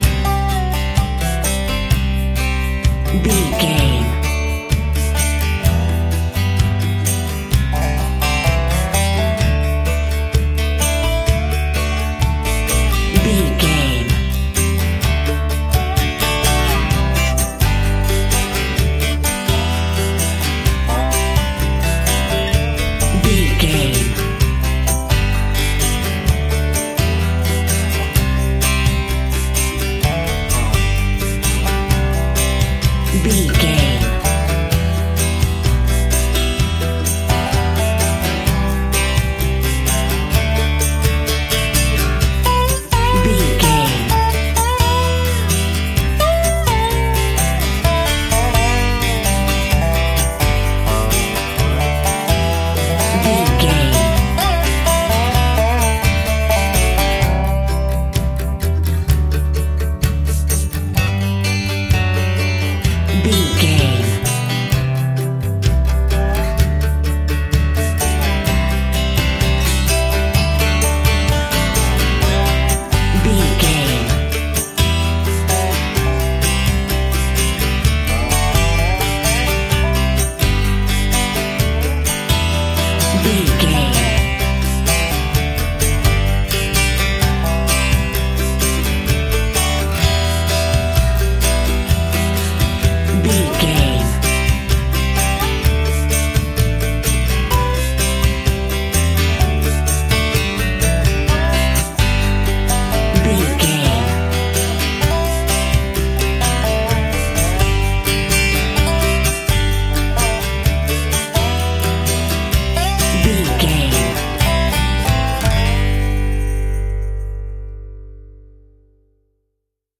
Ionian/Major
acoustic guitar
bass guitar
banjo
Pop Country
country rock
bluegrass
happy
uplifting
driving
high energy